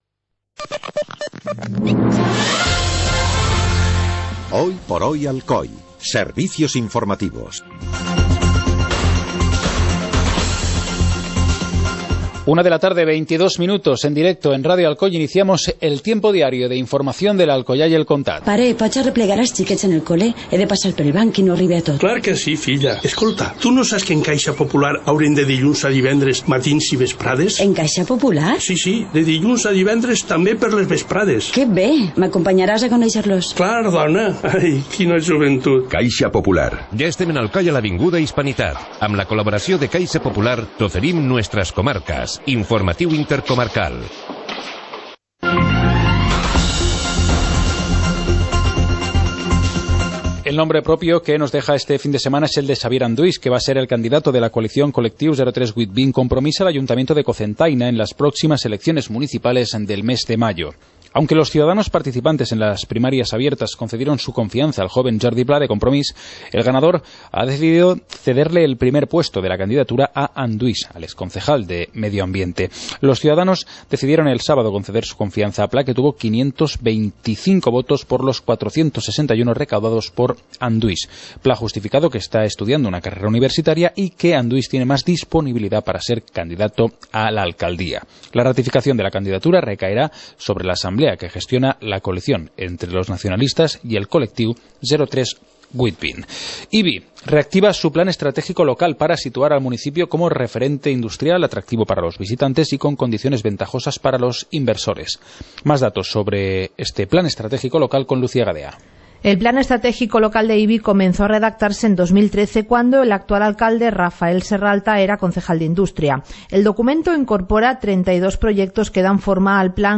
Informativo comarcal - lunes, 26 de enero de 2015